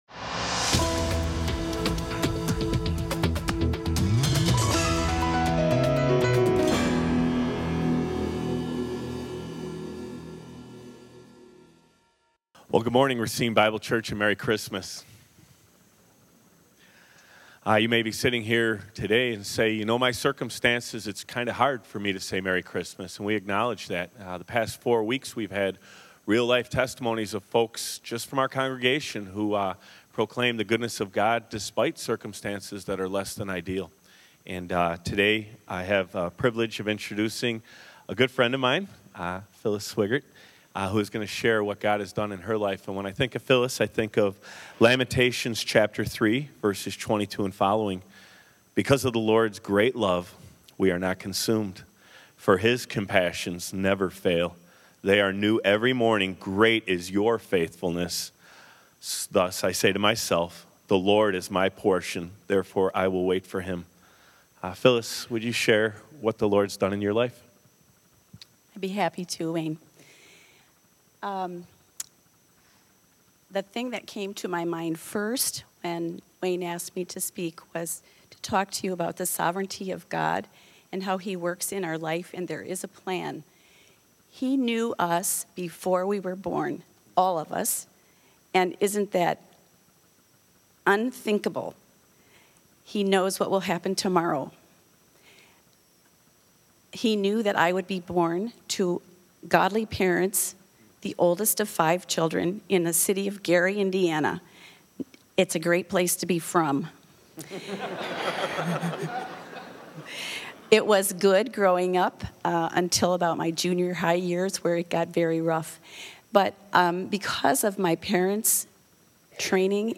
Gospel Sermon